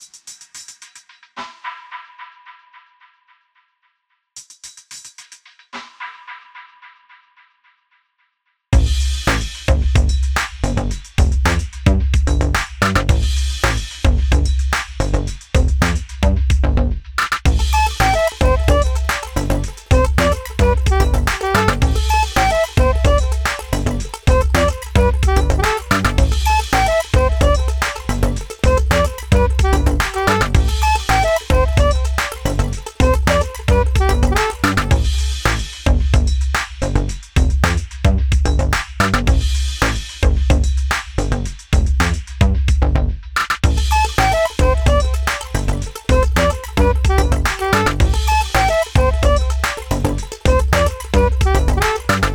タグ: あやしい
な～んか飄々としてる